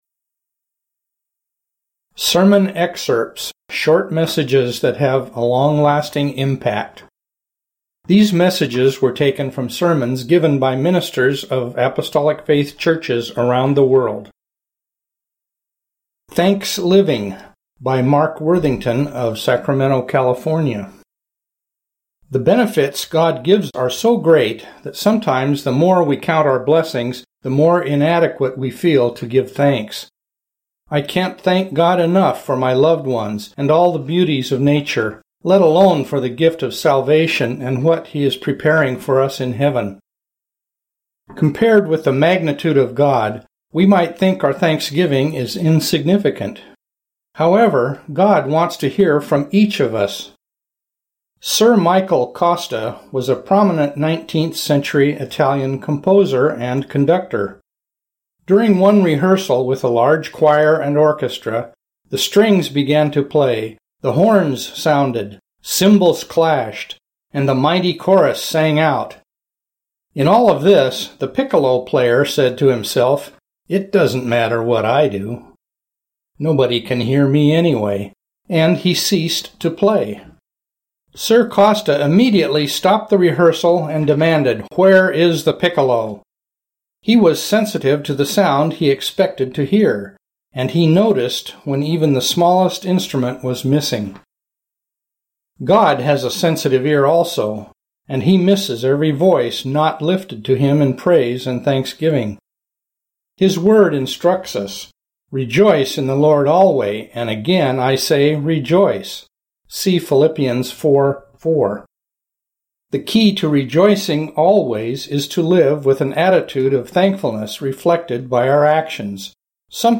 Sermon Excerpts